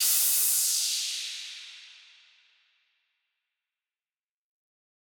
Index of /musicradar/shimmer-and-sparkle-samples/Filtered Noise Hits
SaS_NoiseFilterB-05.wav